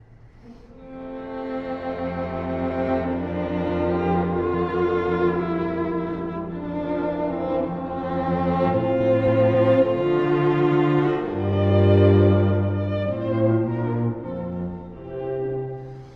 ↑古い録音のため聴きづらいかもしれません！（以下同様）
Adagio non lento
～ゆるやかに、しかし遅くなく～
安らぎのように素敵なカンタービレ。
しかし、唐突にビオラが独白を行い、ほかの楽器も追いかけるようにフーガが始まります。